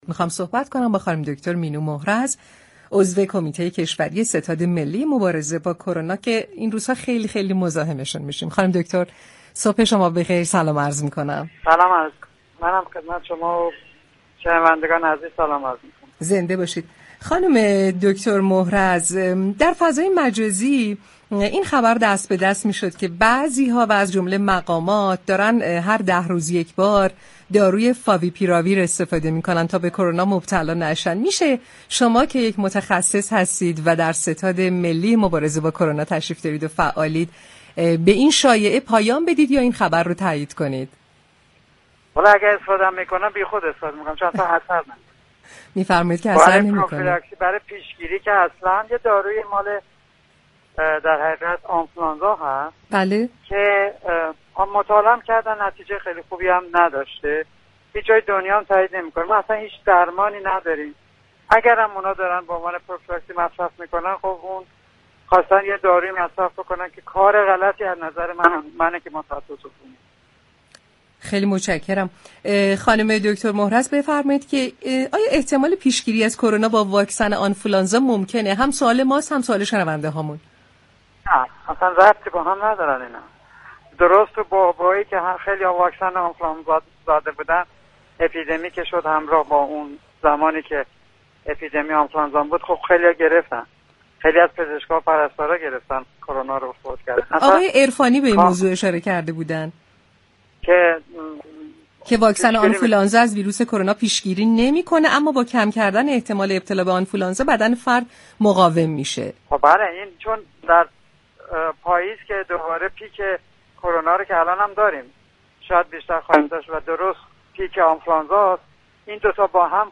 دكتر مینو محرز، عضو كمیته كشوری ستاد ملی مبارزه با كرونا، در گفت‌وگو با تهران كلینیك رادیو تهران در روز شنبه 24 خرداد، شایعاتی مبنی بر تاثیر داروی "فاویپیراویر" در پیشگیری از بیماری كرونا را رد كرده و آن را بی اثر اعلام كرد.